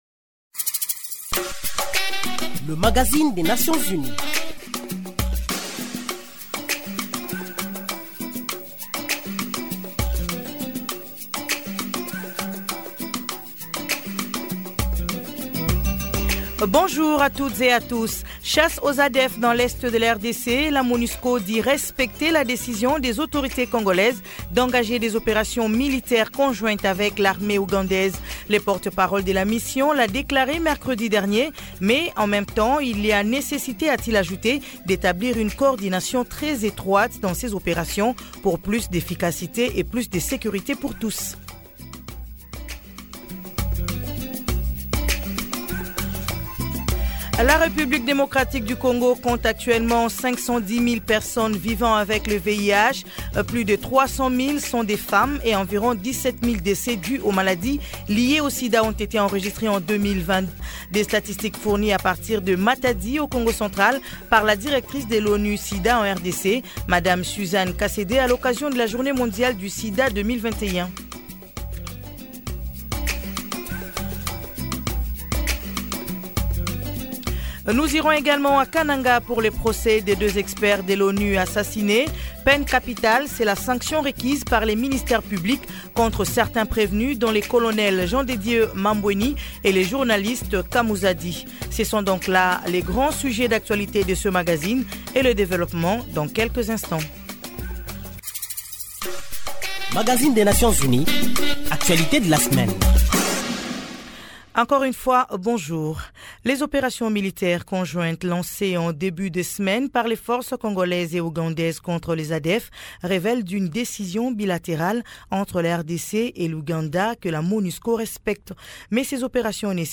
Brèves ***Nos nouvelles en plus bref, le Royaume de Norvège et l’Organisation